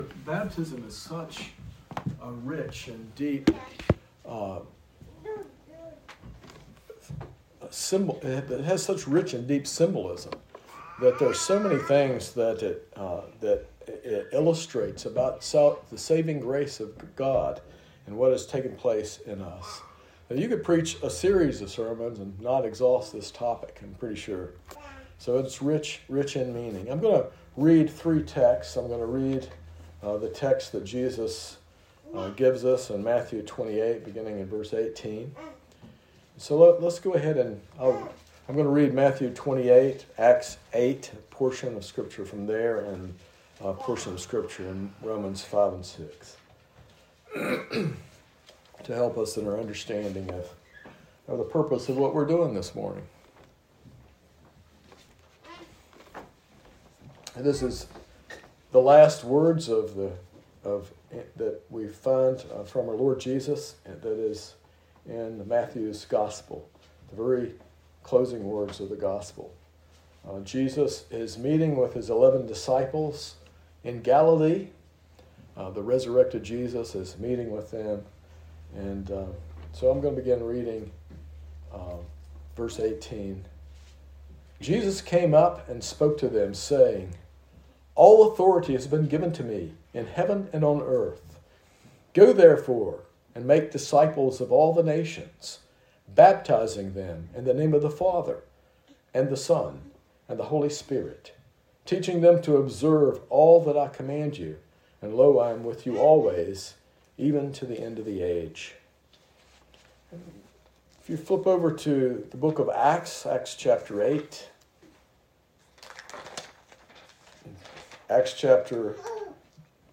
This sermon explores the profound meaning of baptism, emphasizing that it is a public proclamation of faith and identification with Christ’s death, burial, and resurrection, rather than a means of salvation. It highlights how Jesus’ own baptism symbolized his identification with humanity’s sin, allowing believers to be immersed into newness of life.